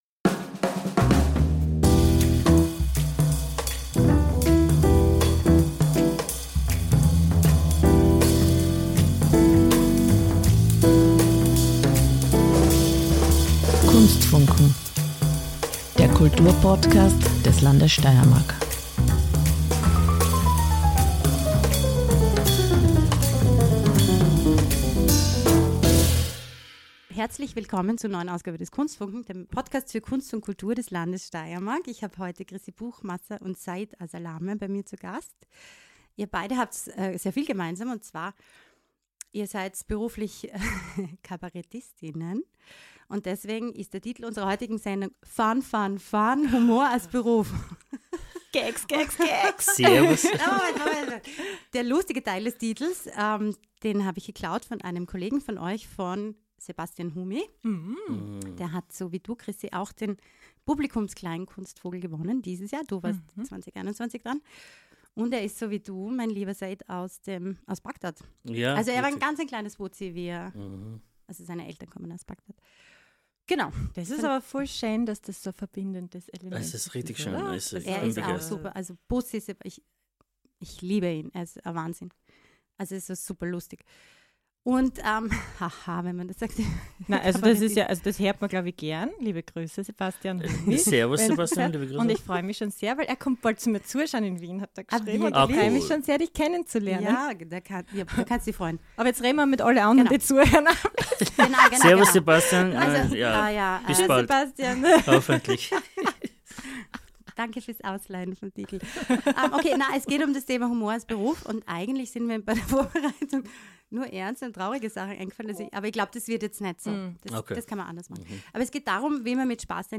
Ein Gespräch über Fotografie